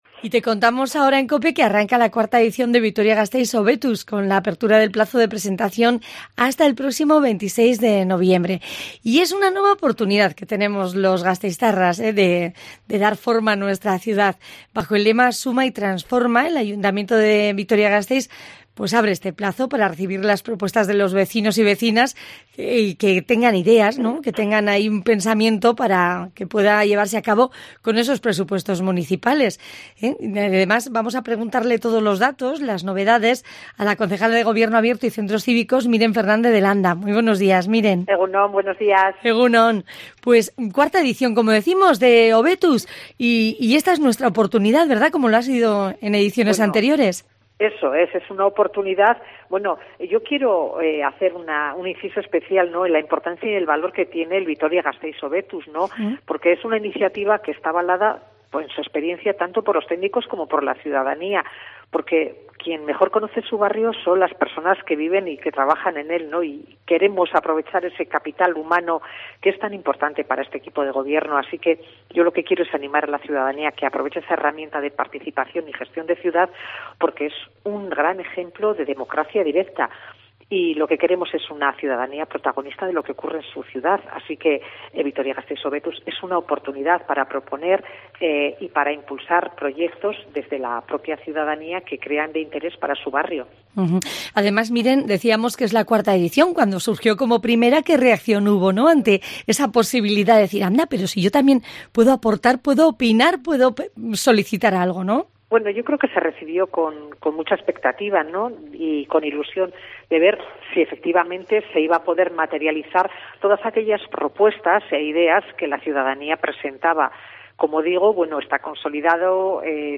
Descubre cómo hacerlo con 'Vitoria-Gasteiz Hobetuz'. Nos lo cuenta la concejala Miren Fdz de Landa